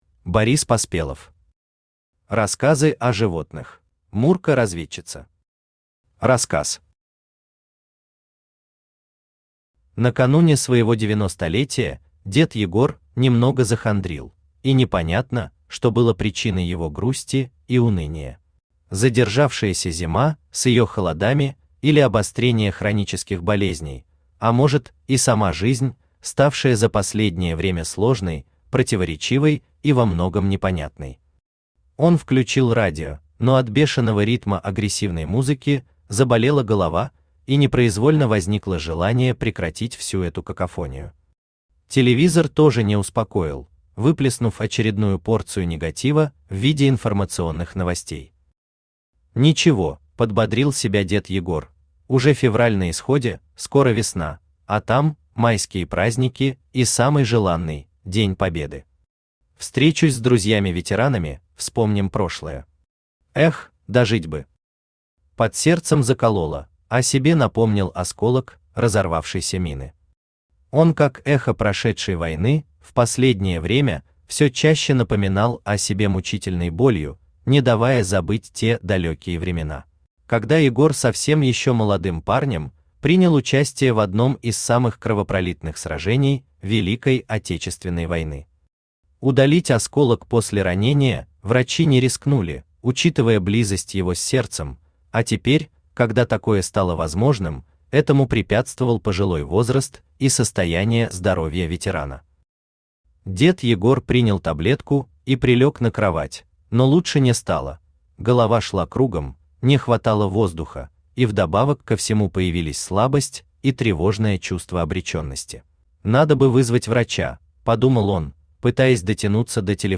Аудиокнига Рассказы о животных | Библиотека аудиокниг
Aудиокнига Рассказы о животных Автор Борис Поспелов Читает аудиокнигу Авточтец ЛитРес.